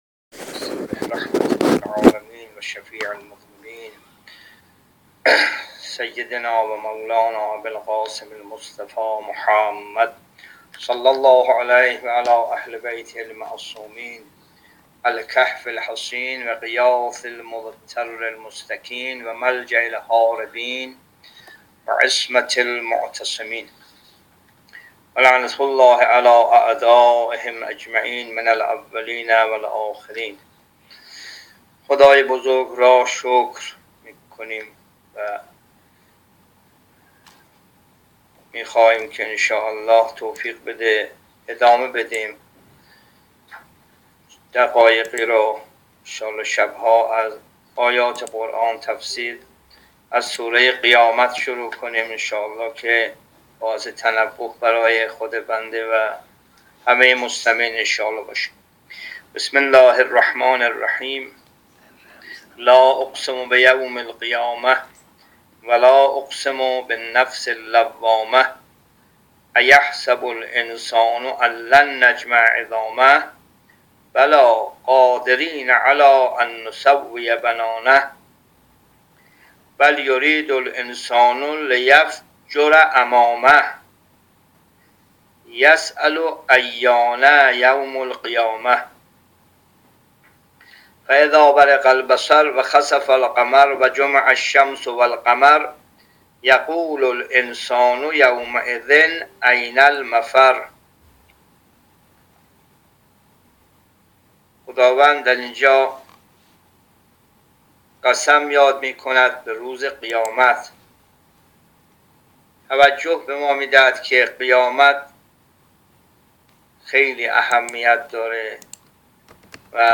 جلسه تفسیر قرآن